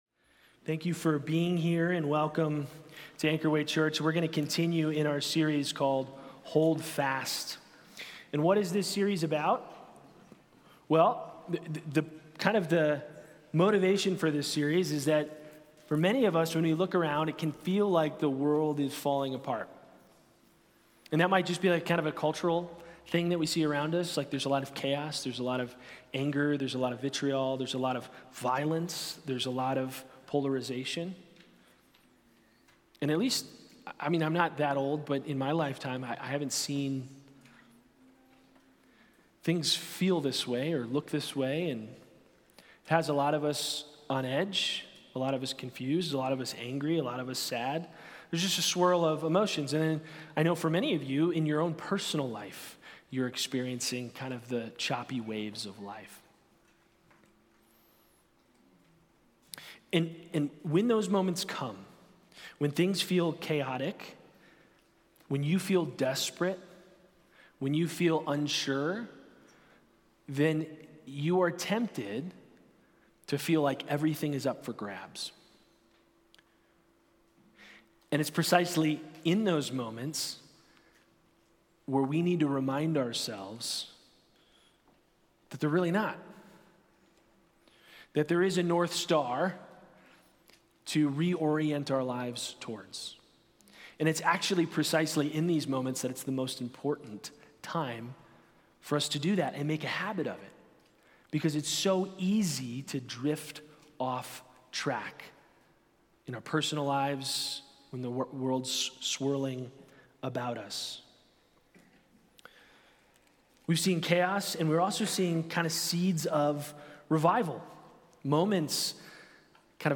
Sermons | Anchor Way Church